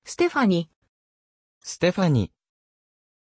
Pronunciation of Stéphanie
pronunciation-stéphanie-ja.mp3